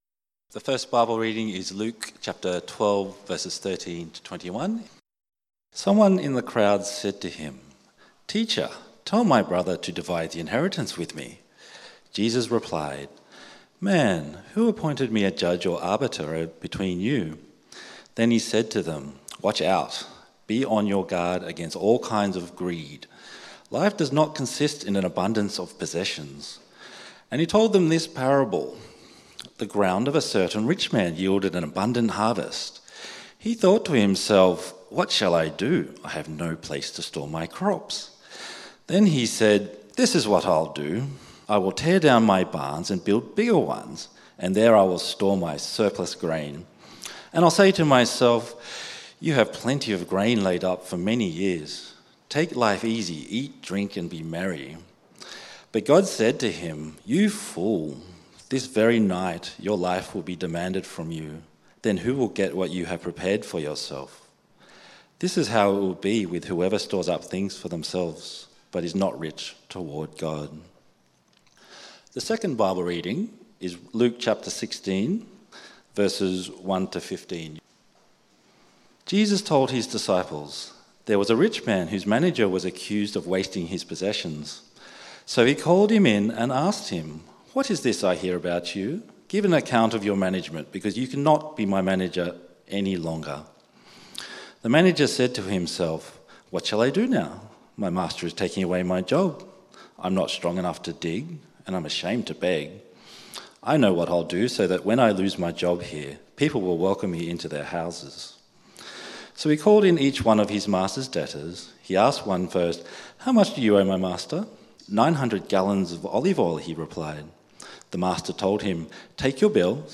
Gospel Generosity Sermon outline